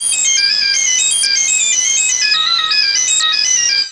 Lone Piper G 122.wav